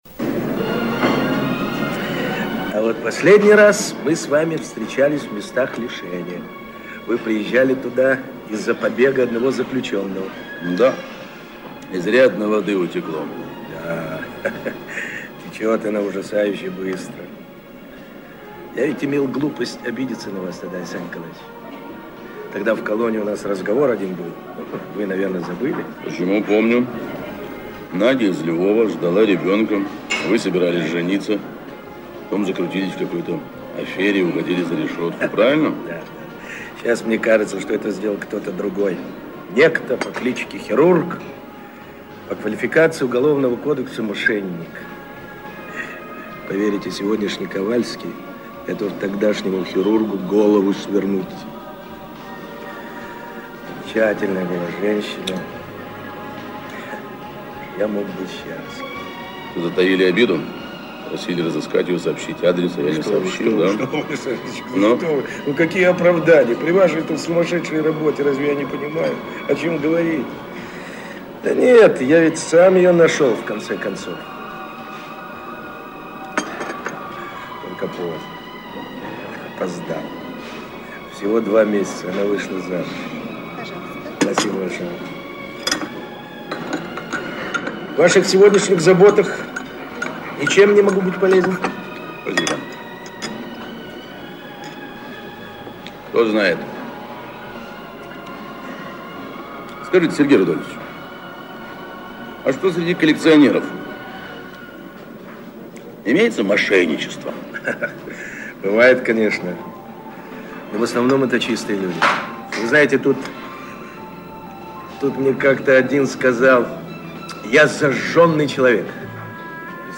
В сериале "Следствие ведут Знатоки" в некоторых эпизодах фоном слышно трансляцию радиостанции "Маяк"
В первом отрывке из серии 14(79год) вторую пьесу(с 1м 48с) слышно более отчетливо-такая знакомая мелодия!